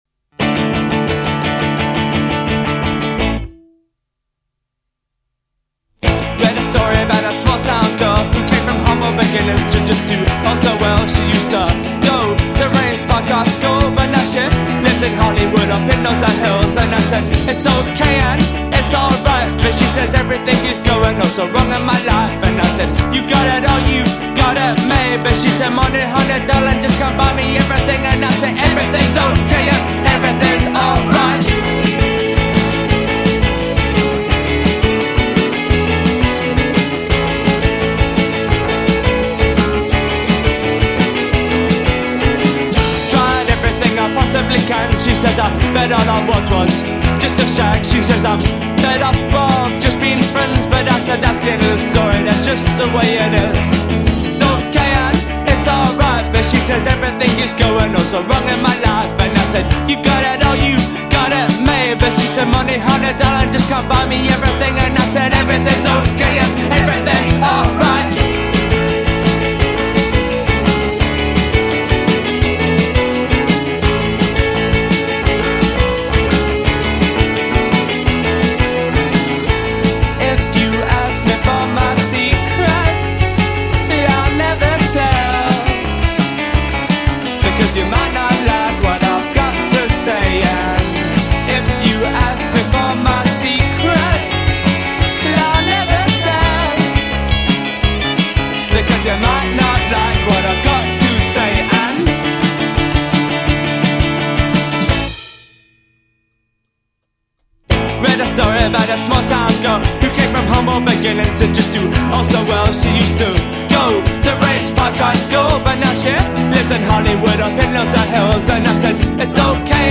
demo